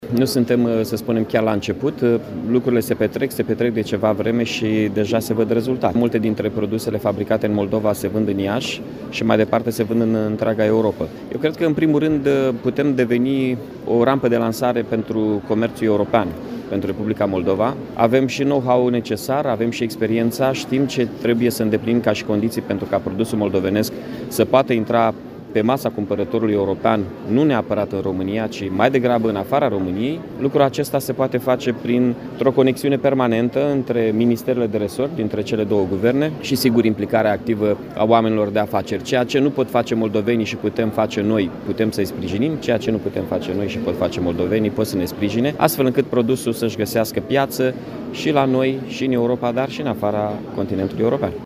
La eveniment este prezent și primarul Iașului, Mihai Chirica. El a arătat că multe dintre produsele fabricate peste Prut se găsesc deja pe piața din Iași și nu numai, și că, în continuare Iașul poate deveni o rampă de lansare pentru comerțul din Republica Moldova.